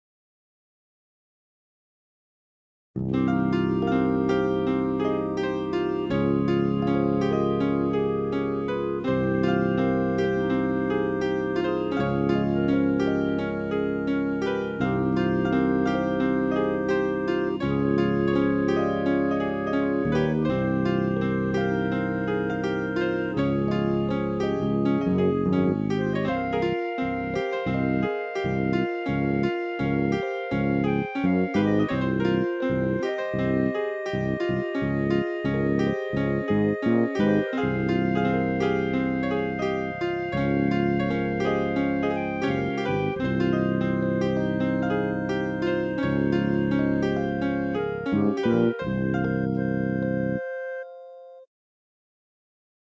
Short tune Flute with Electric Piano